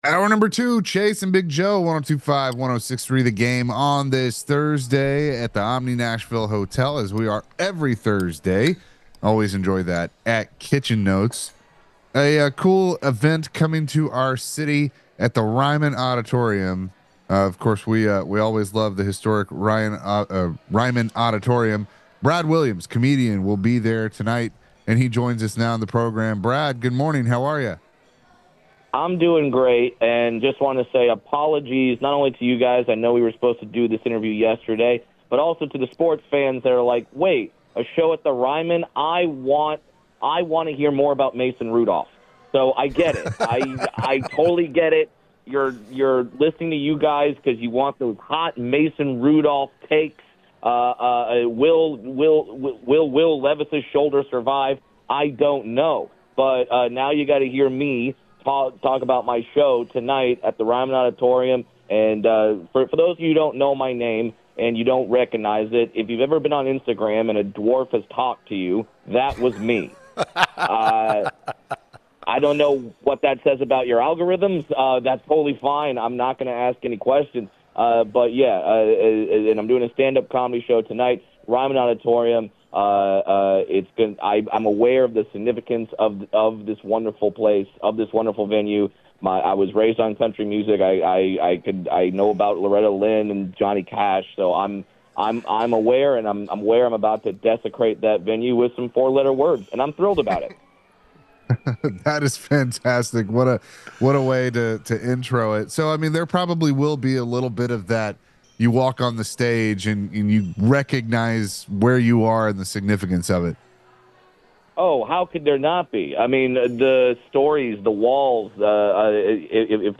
Comedian Brad Williams joined the show to talk about his show tonight at Ryman Auditorium.